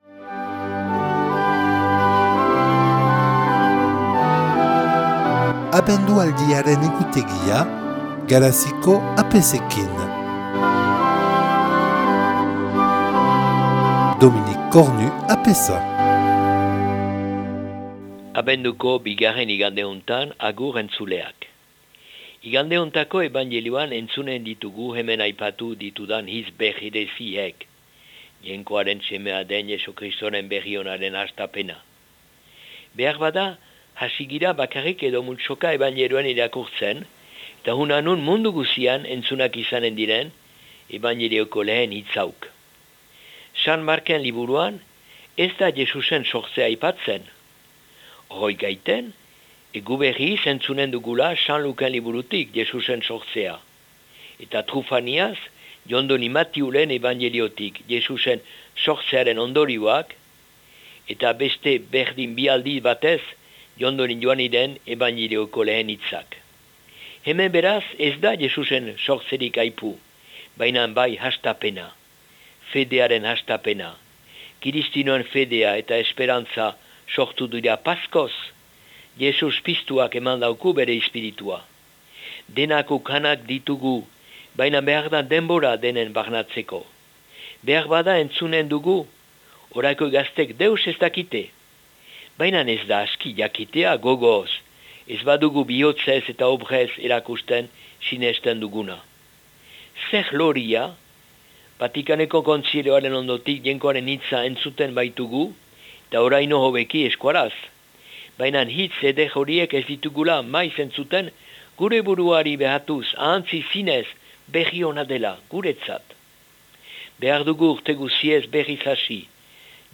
Les prêtres de la Paroisse ont un billet quotidien sur Radio Lapurdi en Basque. Diffusion du message de l'Avent, chaque jour à 7h25, 12h25, 15h10 et 20h25